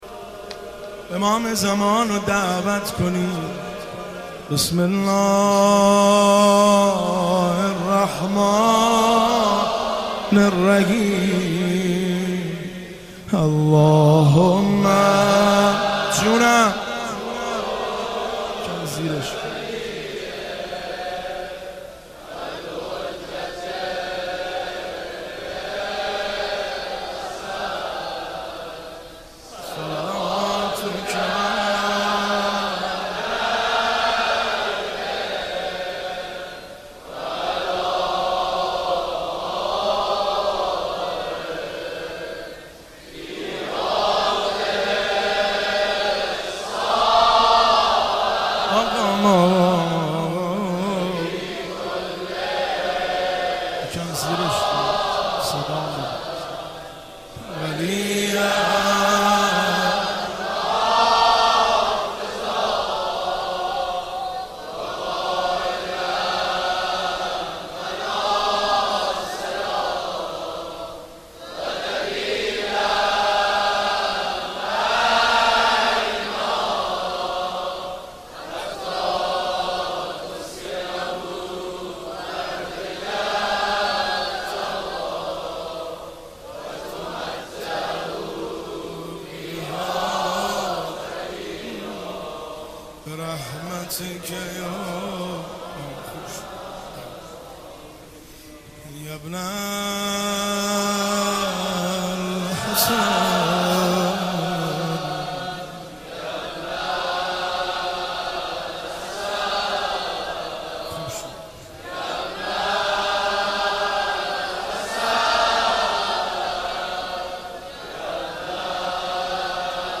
دانلود مداحی نامت بر لبم - دانلود ریمیکس و آهنگ جدید